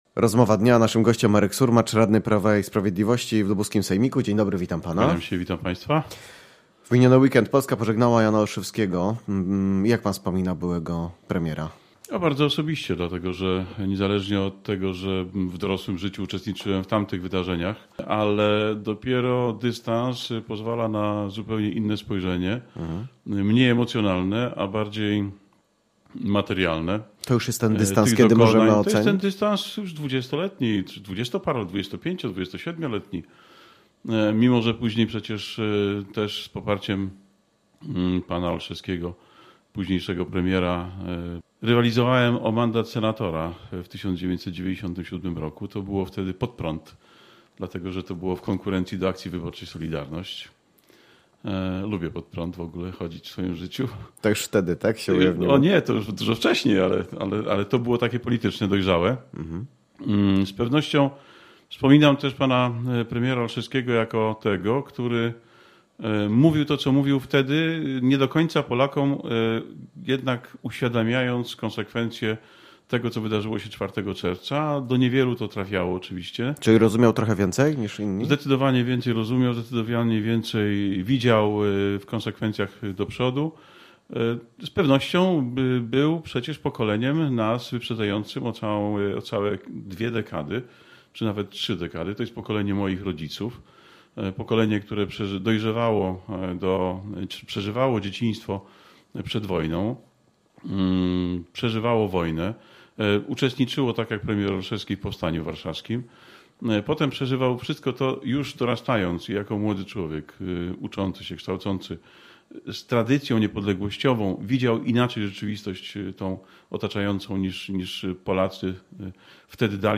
Z radnym wojewódzkim PiS